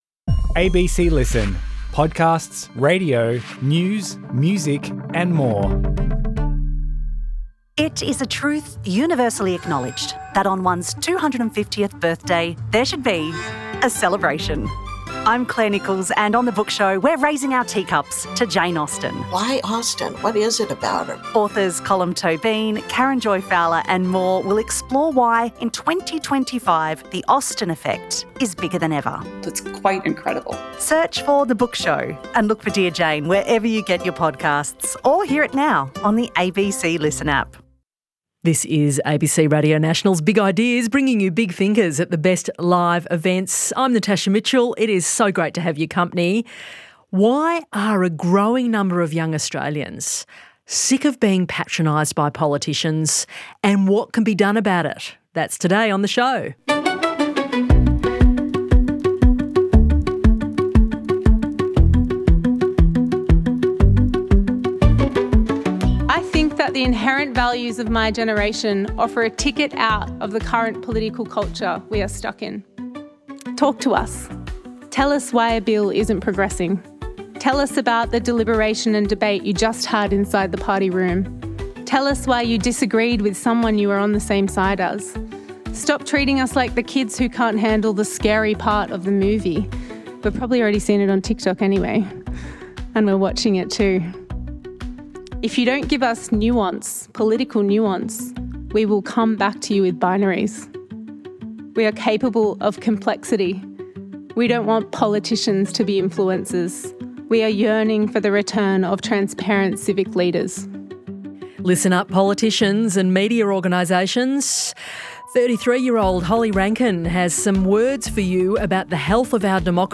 four incredible speakers as they share honest, powerful stories about revealing their identities on their own terms. From fear to freedom, isolation to community - this is a conversation about truth, courage, and connection.